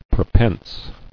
[pre·pense]